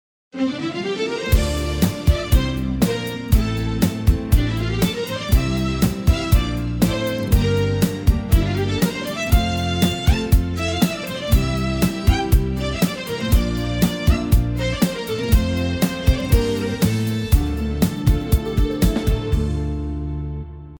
آهنگ زنگ